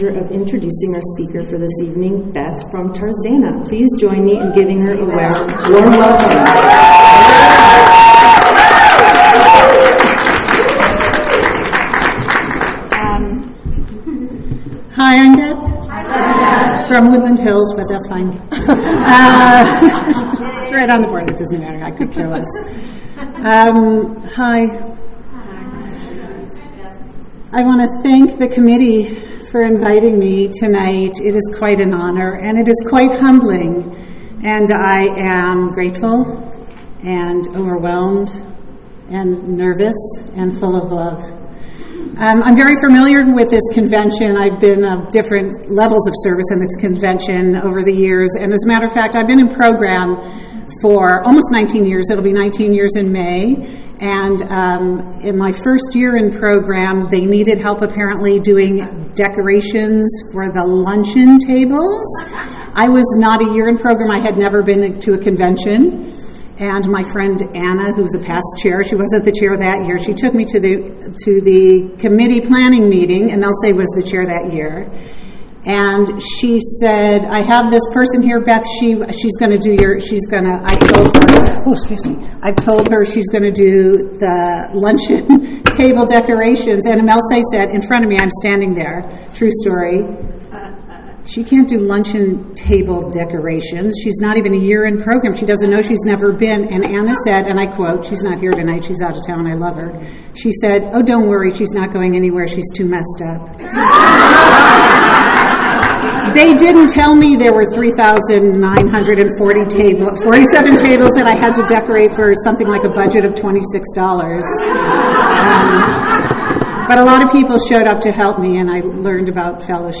49th San Fernando Valley Alcoholics Anonymous Convention